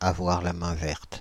Ääntäminen
IPA: /a.vwaʁ.la.mɛ̃.vɛʁt/